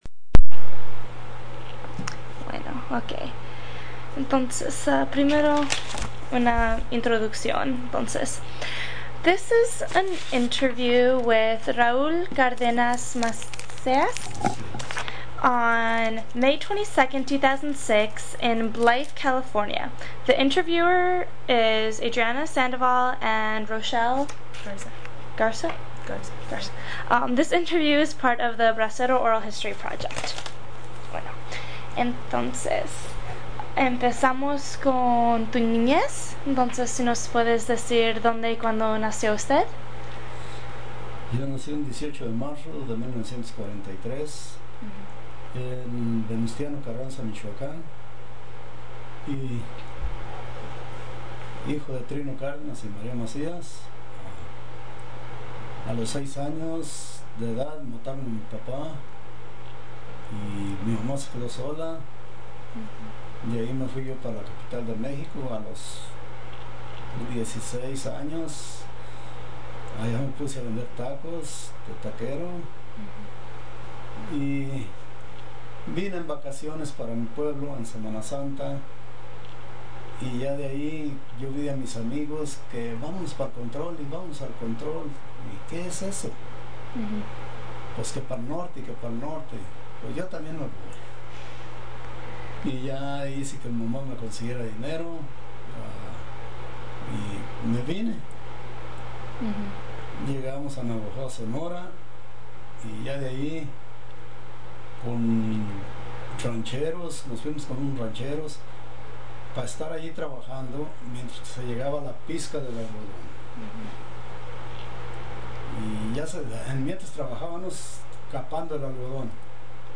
Location Blythe, CA Original Format Mini disc